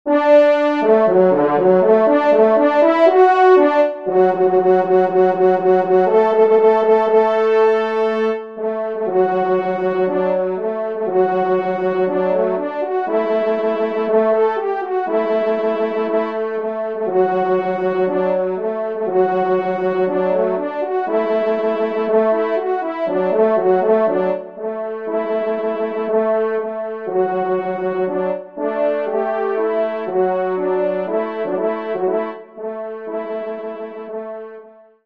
4e Trompe